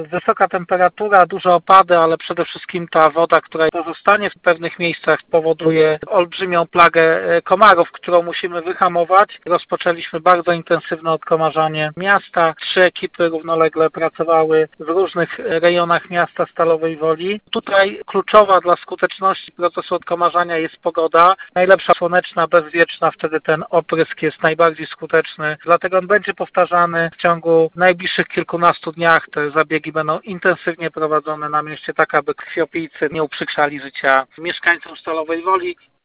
Mówi prezydent Stalowej Woli Lucjusz Nadbereżny: